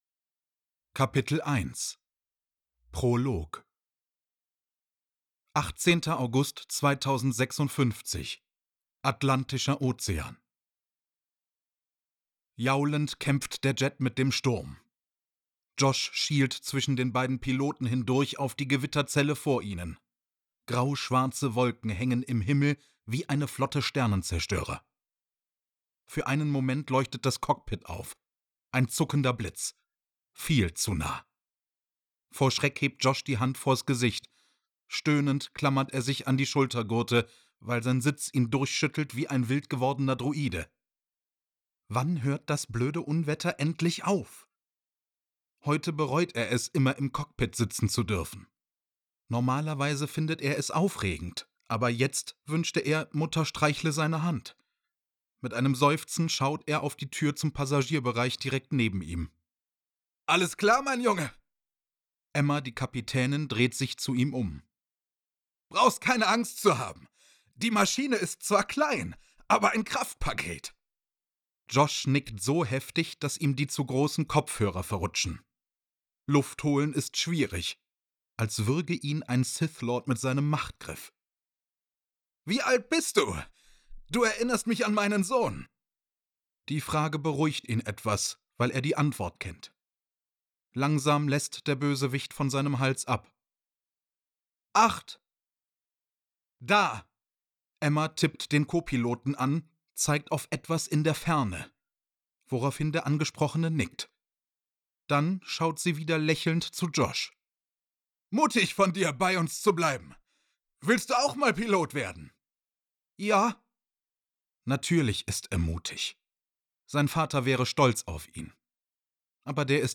Hörbuch | Thriller